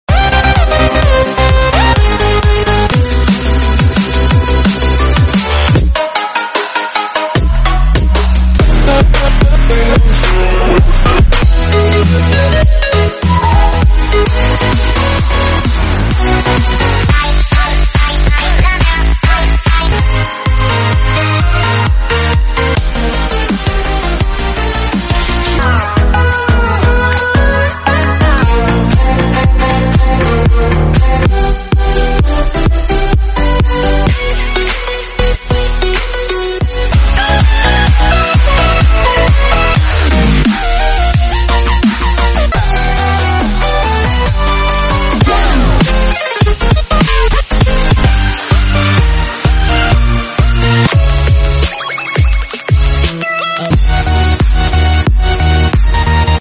House, Future, Bass, Dubstep